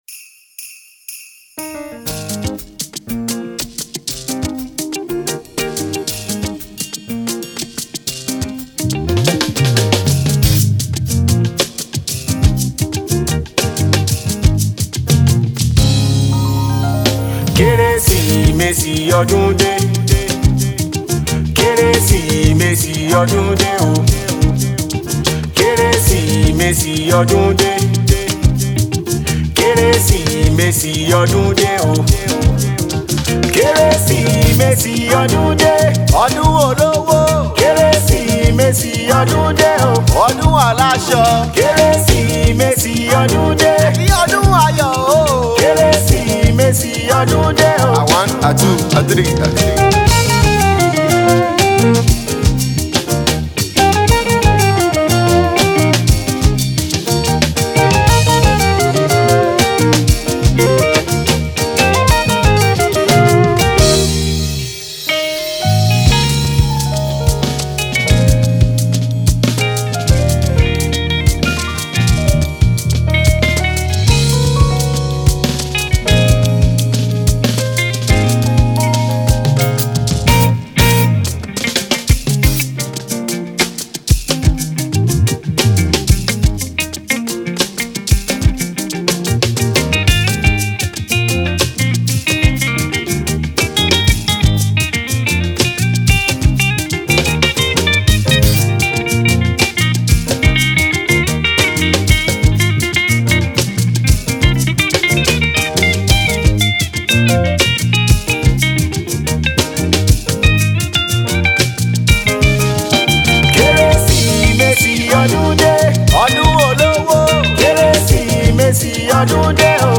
Afro Jazz
guitarist
soulful Afro Jazz track infused with Yoruba lyrics
This song will definitely get you moving and dancing.